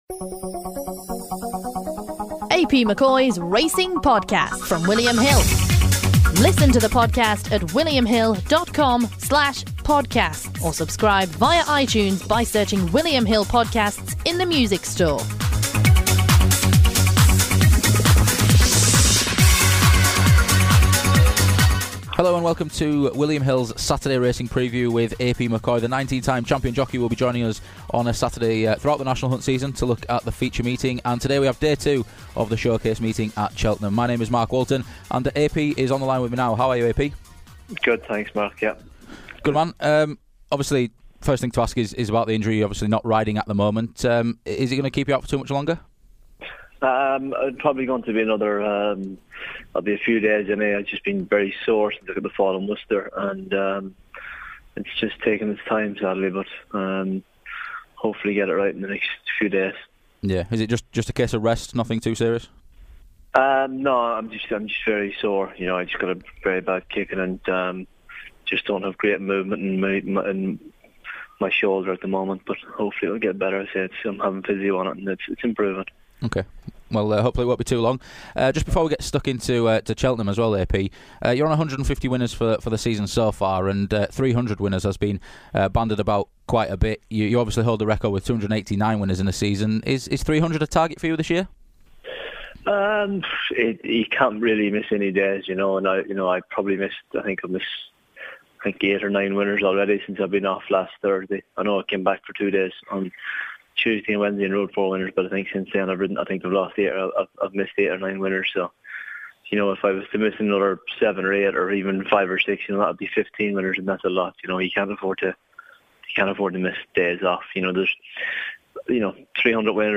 The new National Hunt season kicks up a gear with the Showcase Meeting at Cheltenham and Champion Jockey AP McCoy previews Saturday's card.